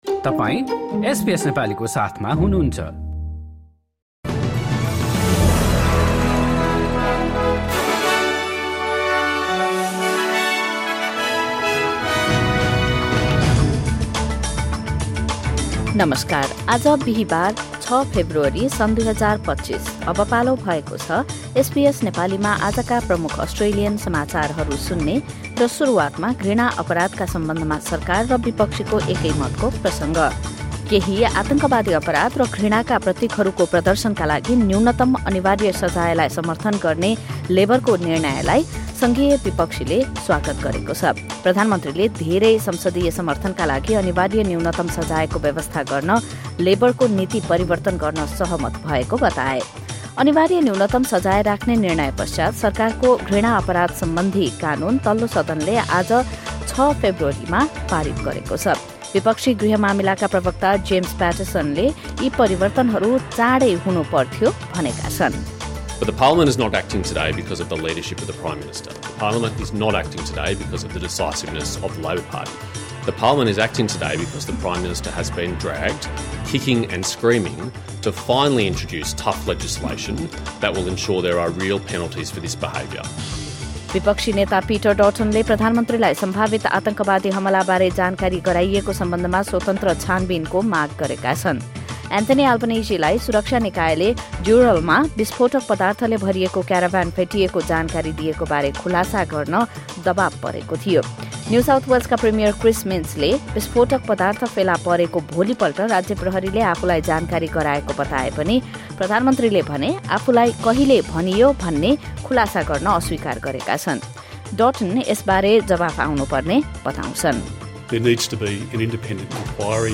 SBS Nepali Australian News Headlines: Thursday, 6 February 2025